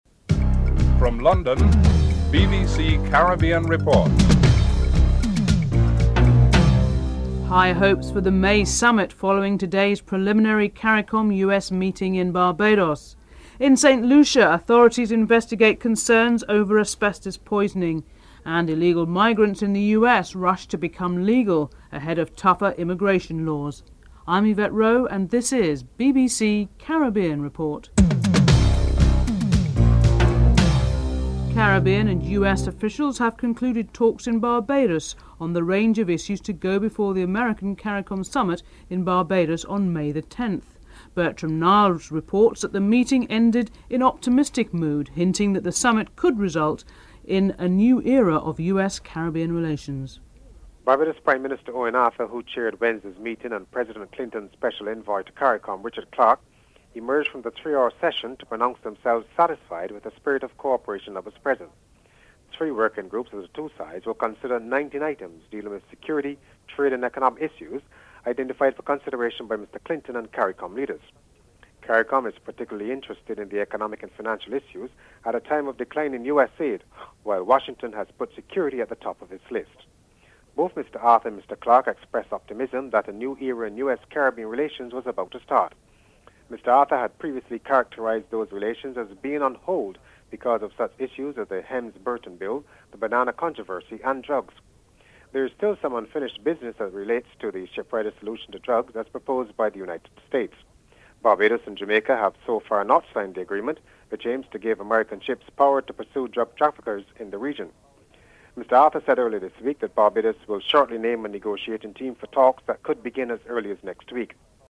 1. Headlines (00:00-00:30)
8. Larry Gomes, former West Indies test bastman says that Canada is capable of a third straight victory in the International Cricket Council Trophy Tournament in Guadalupe. Larry Gomes is interviewed (13:16-15:27)